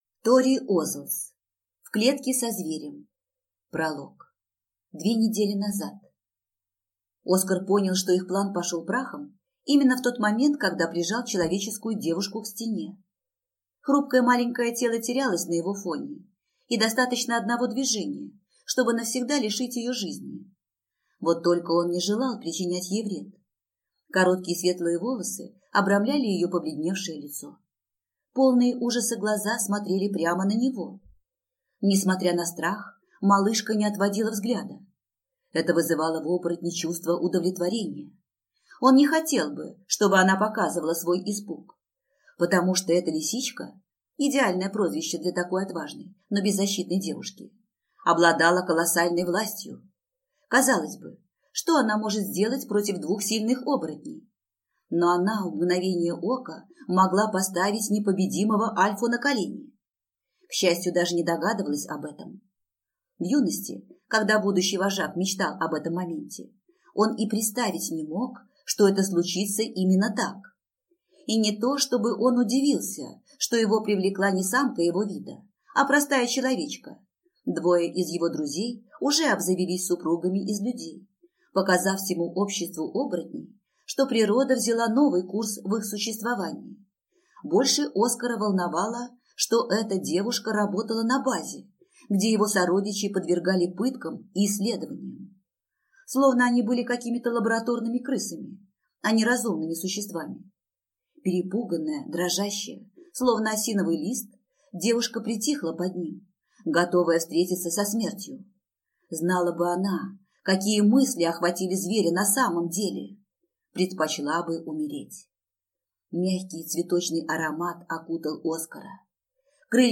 Аудиокнига В клетке со зверем | Библиотека аудиокниг